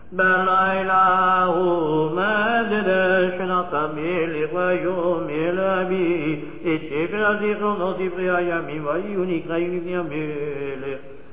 The following verses are sung by the kahal, and repeated by the Chazzan: